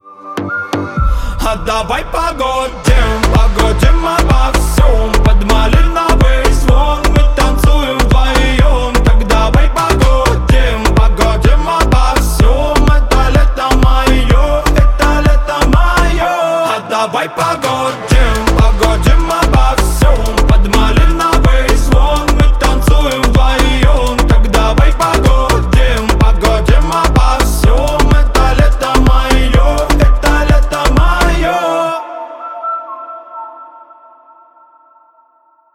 поп
мужской голос
веселые